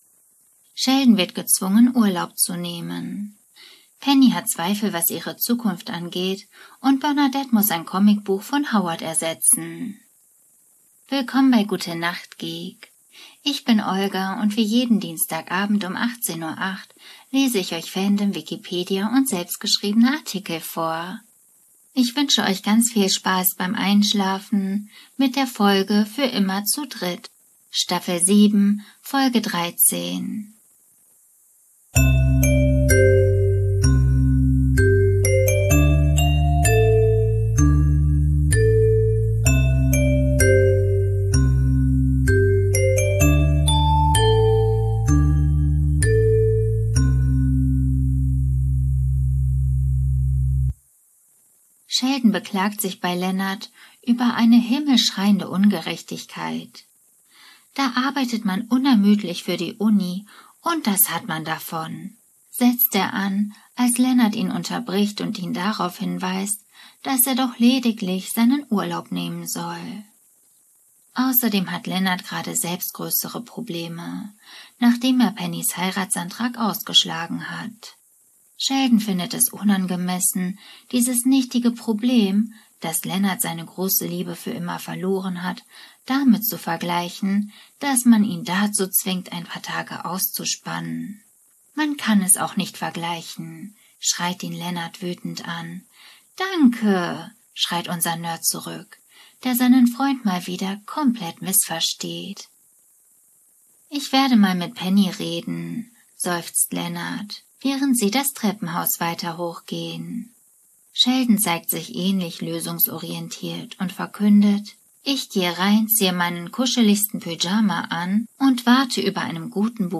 Lehn dich zurück, kuschle dich ins Bett ein oder auf die Couch und lass dich sanft ins Traumland lesen.